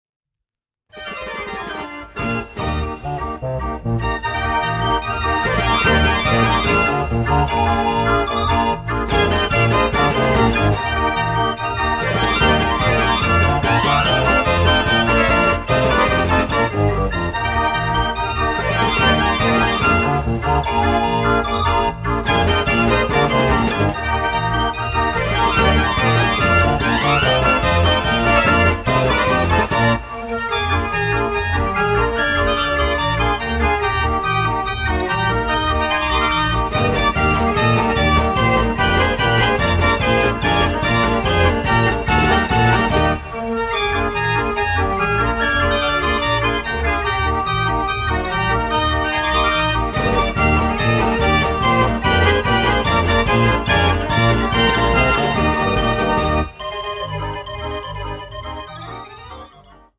Uitvoering Stereo / Mono